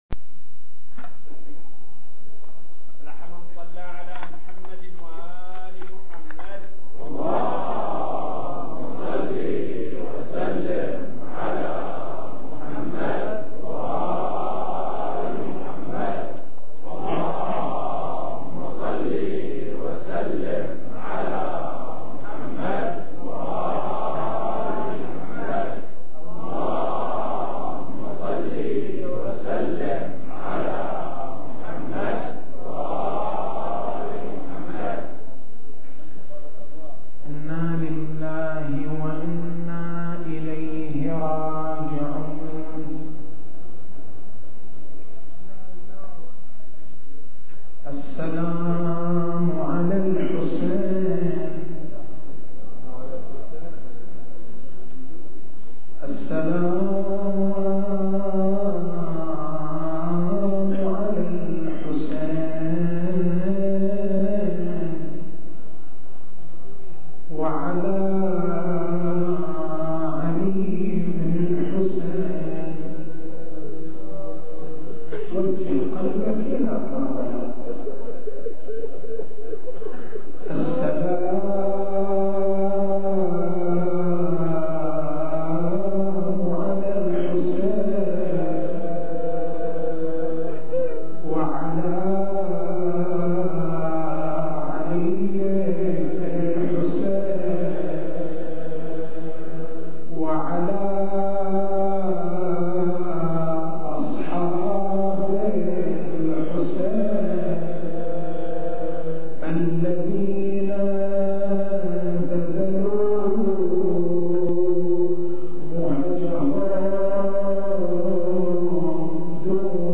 تاريخ المحاضرة: 09/01/1426 نقاط البحث: إجماع الأمة على عظمة أئمة التشيّع عظمة المعالم الفكرية للتشيع فتح باب الاجتهاد الانسجام مع العقل الانسجام مع القرآن الكريم سموّ الآفاق الروحية مظلومية أهل البيت (ع) التسجيل الصوتي: تحميل التسجيل الصوتي: شبكة الضياء > مكتبة المحاضرات > محرم الحرام > محرم الحرام 1426